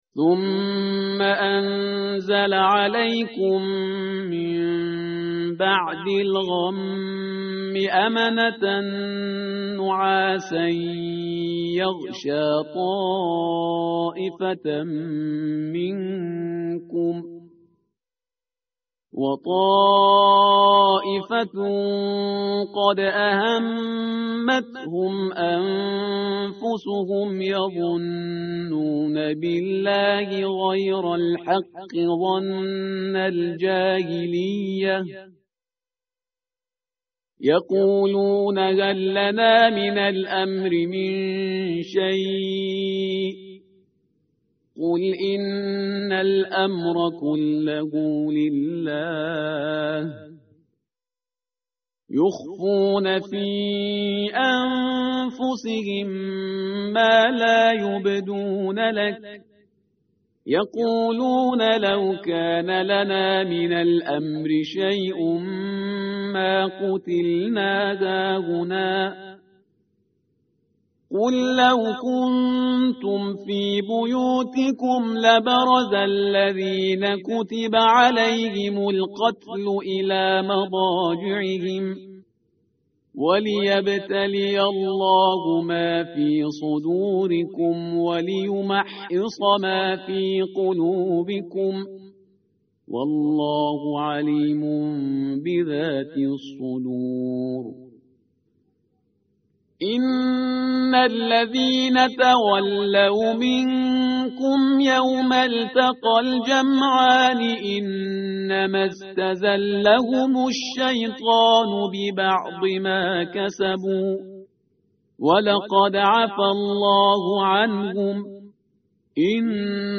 tartil_parhizgar_page_070.mp3